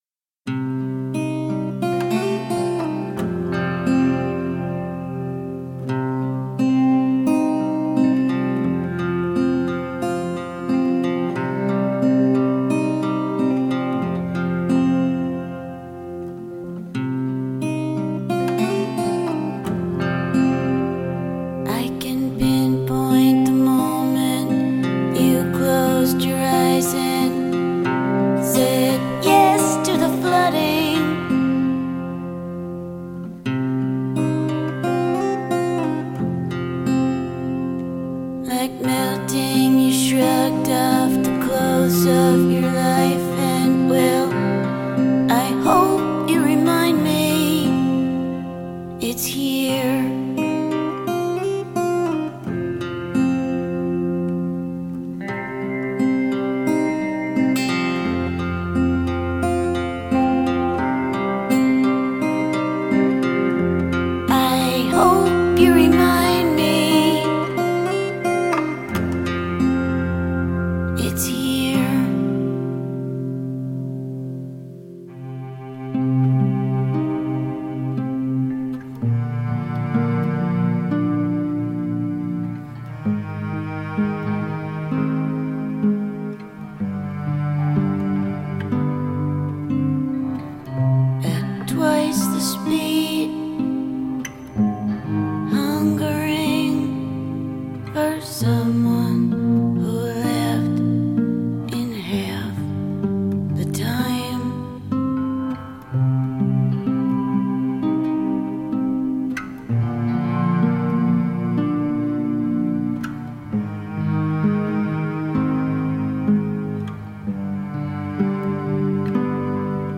chansons rock
ballades plus personnelles
la voix râpeuse